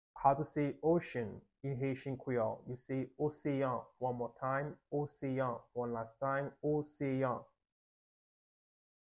Pronunciation:
10.How-to-say-Ocean-in-Haitian-Creole-–-oseyan-with-pronunciation.mp3